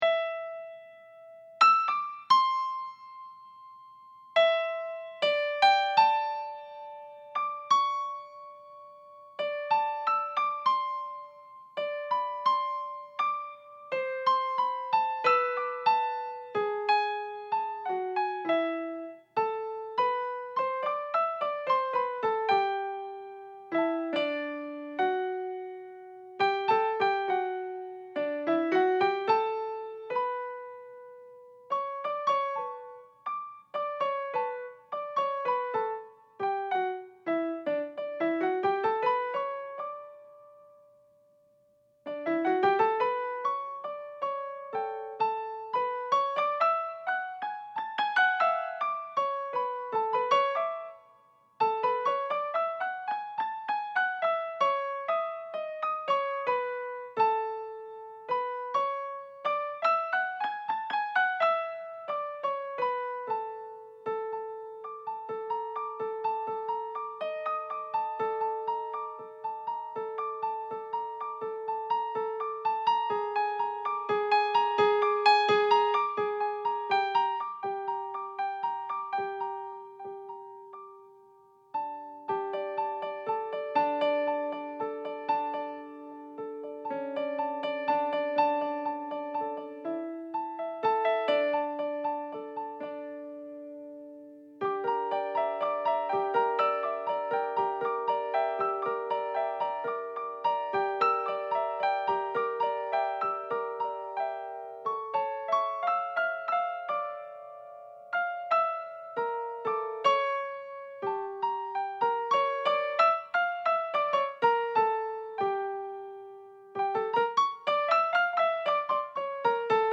Thème connu que j’ai associé à un beat que je pratique depuis longtemps.
Avec sparages presque orientales.